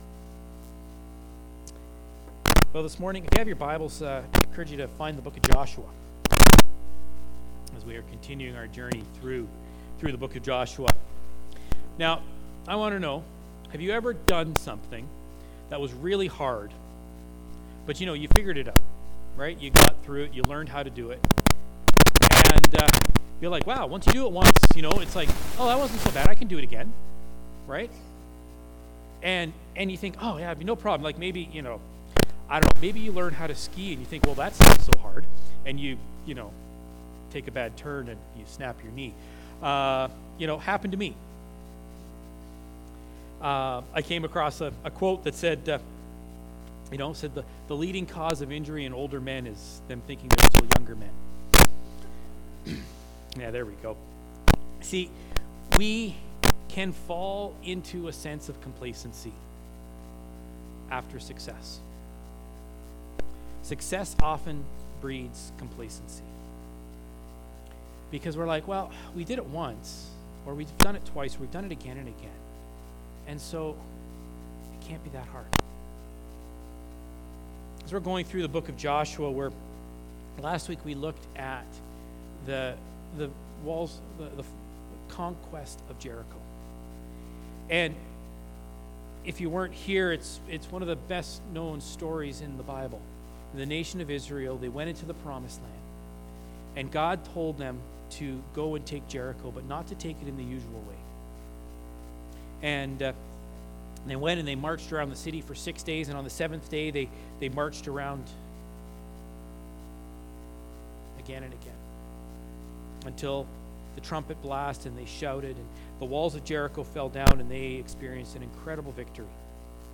Sermons | Central Fellowship Baptist Church